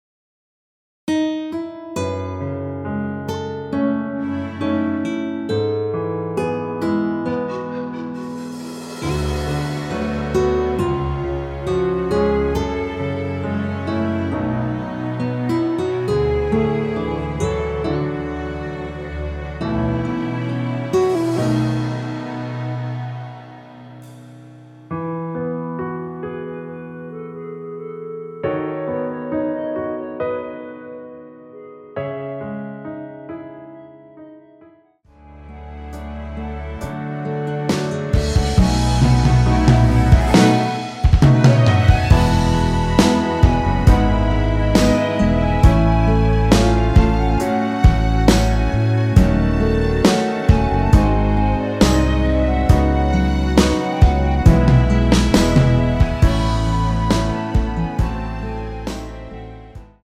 원키에서(-1)내린 멜로디 포함된 MR입니다.(미리듣기 확인)
멜로디 MR이란
앞부분30초, 뒷부분30초씩 편집해서 올려 드리고 있습니다.
중간에 음이 끈어지고 다시 나오는 이유는